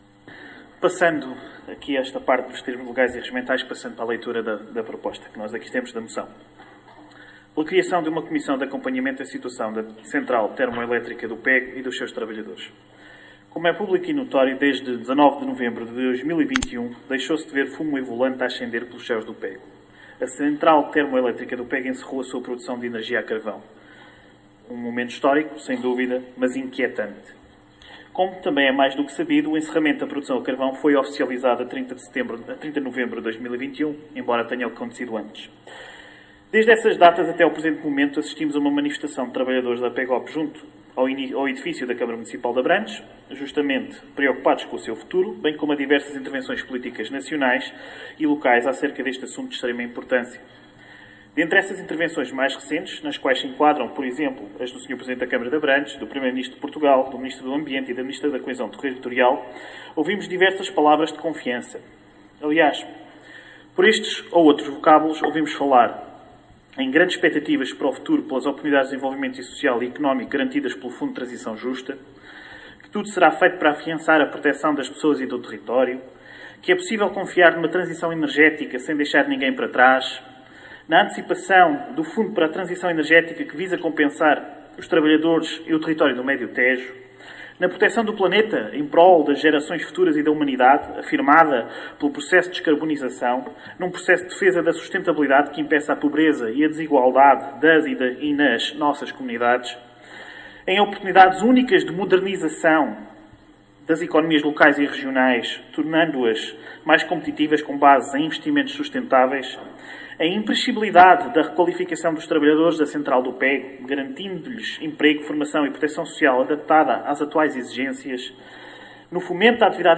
ÁUDIO | DEPUTADO DO PSD, JOÃO SALVADOR FERNANDES